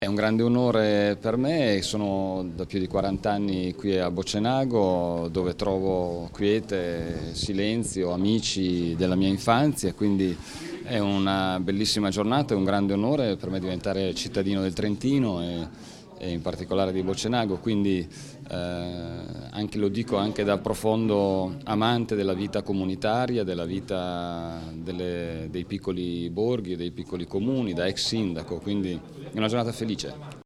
intervista_Delrio.mp3